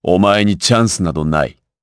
Lusikiel-Vox_Skill4_jp.wav